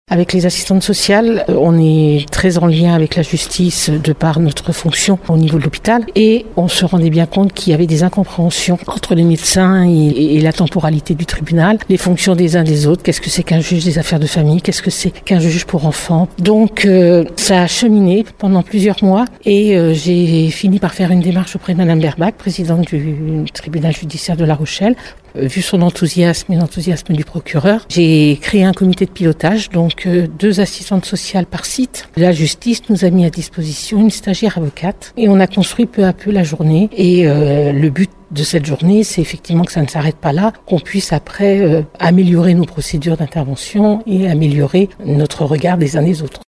Une rencontre hôpital-justice s’est déroulée hier au palais des congrès de Rochefort. Plus de 180 professionnels de santé, magistrats, fonctionnaires de police et de la gendarmerie, mais aussi du monde judiciaire se sont retrouvés pour échanger.